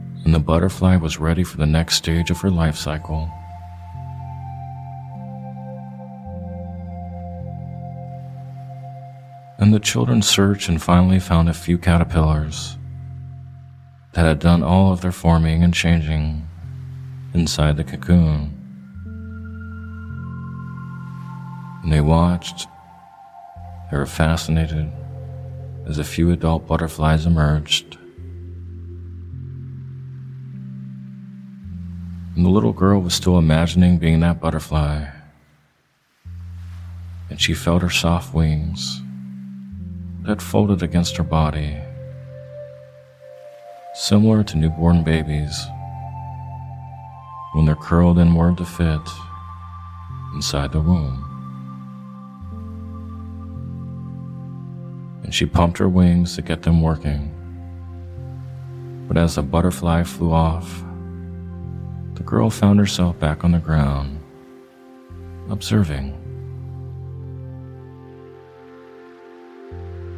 Butterfly Meditation (Story Based Hypnosis)
In this meditation, you’ll be guided via a story based metaphor of a Caterpillar transforming into a butterfly. A great metaphor for personal transformation and growth.